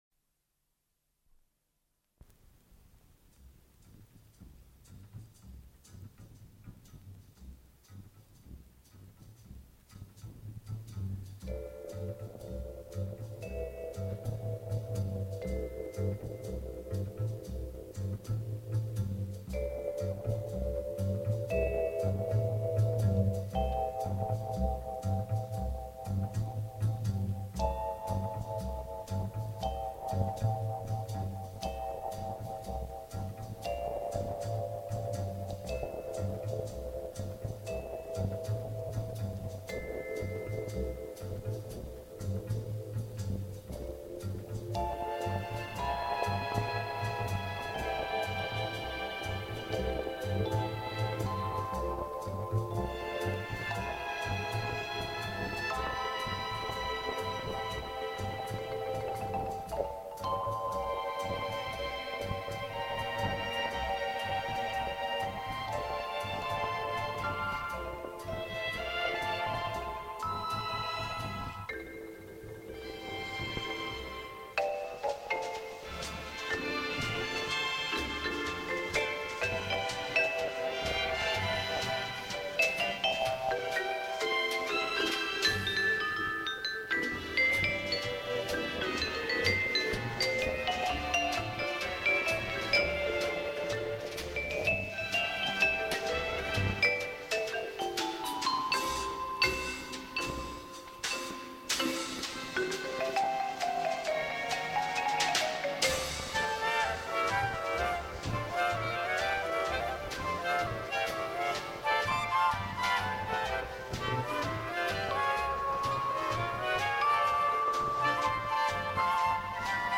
Кроме того, можете ещё и послушать, что получается при сложении звуковых каналов Вашей оцифровки.